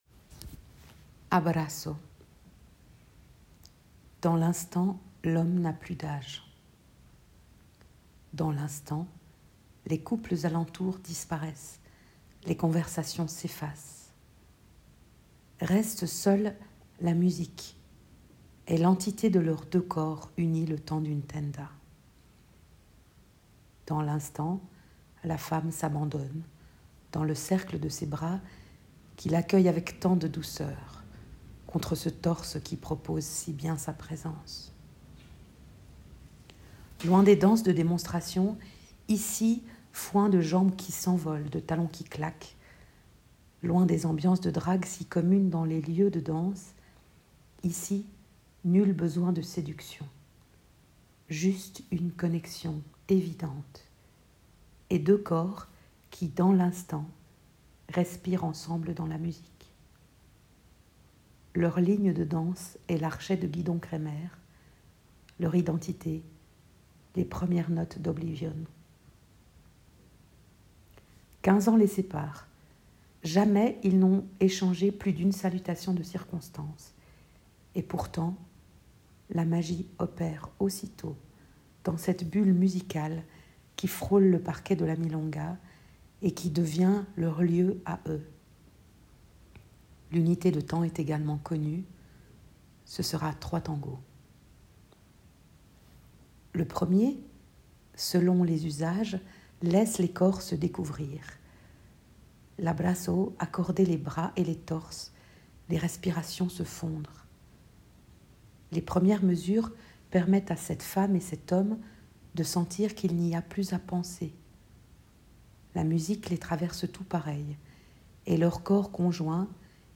Lien musical: Gidon Kremer « Hommage à Piazzolla »